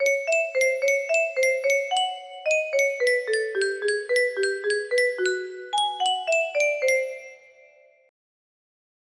music box melody